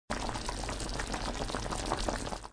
煮东西.mp3